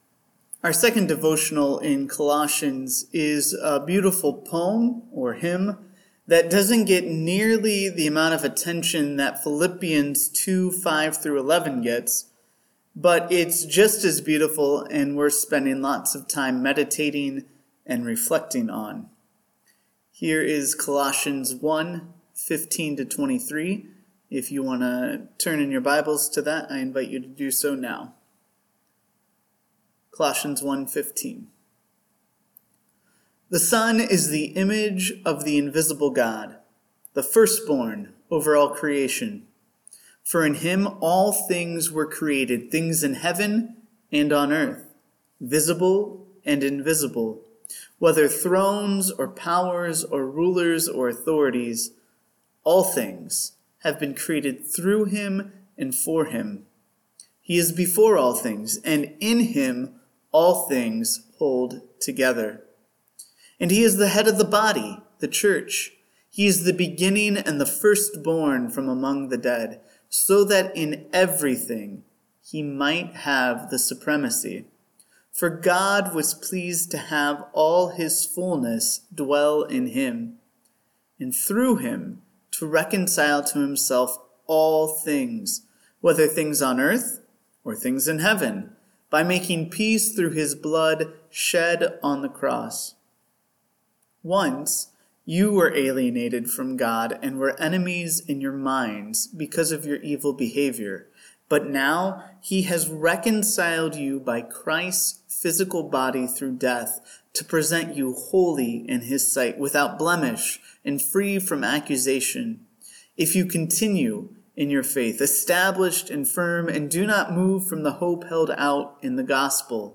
All Things – Devotional